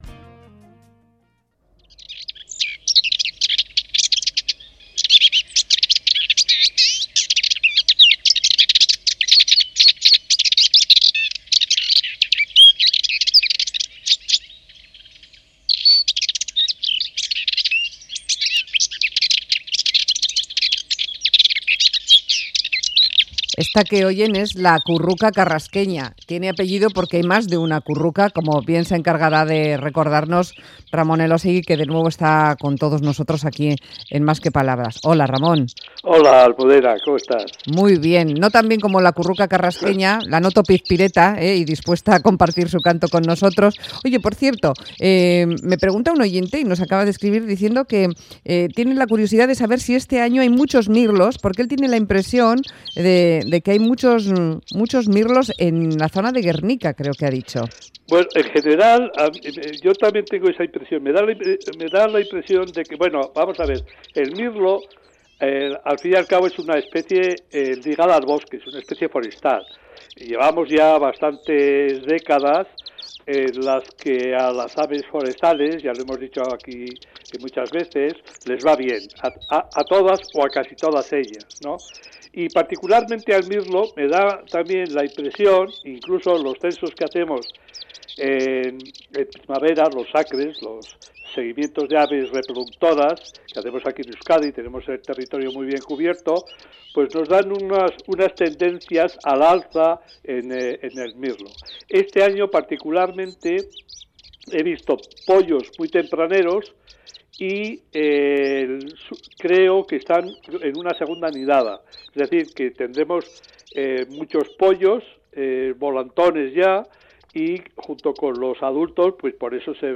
un tipo de pájaro nervioso y esquivo de ver entre los matorrales donde se oculta.